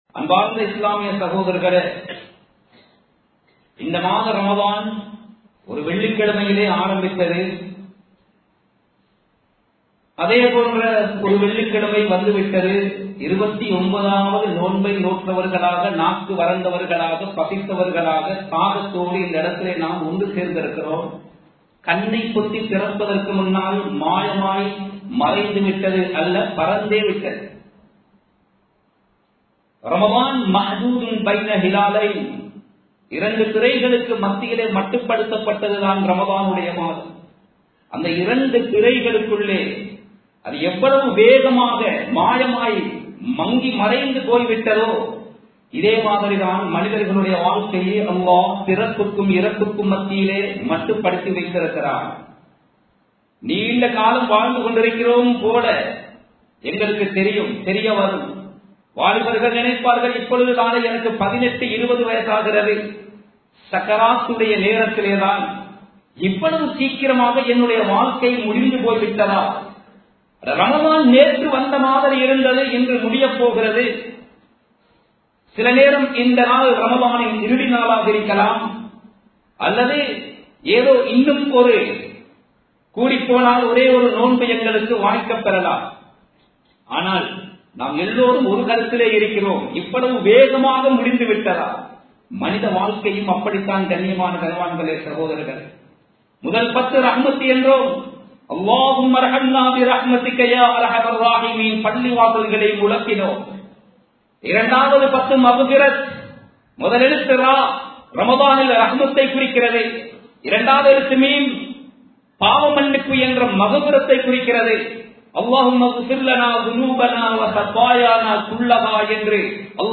உண்மையான தௌபா | Audio Bayans | All Ceylon Muslim Youth Community | Addalaichenai